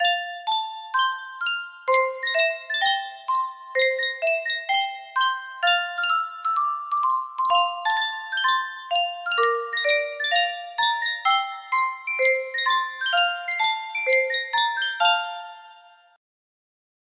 Sankyo Music Box - John Jacob Jingleheimer Schmidt TBE music box melody
Full range 60